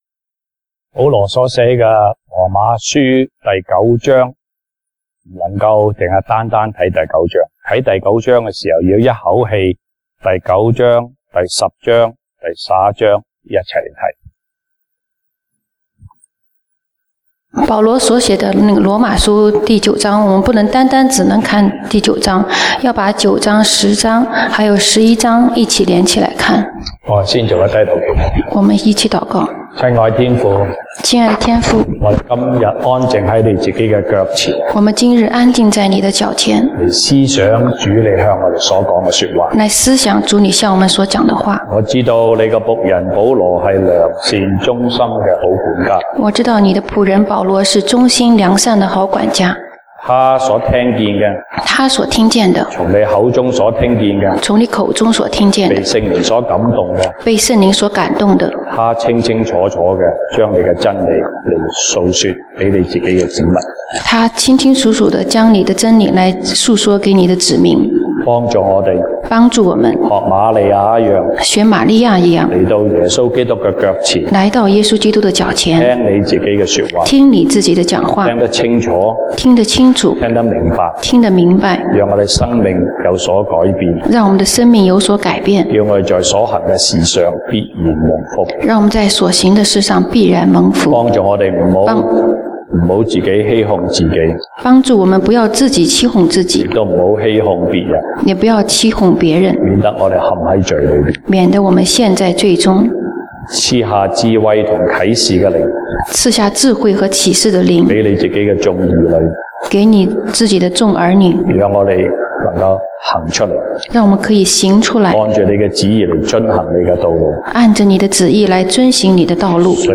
西堂證道(粵語/國語) Sunday Service Chinese: 用愛心說真理
Passage: 羅馬書 Romans 9:1-5 Service Type: 西堂證道(粵語/國語) Sunday Service Chinese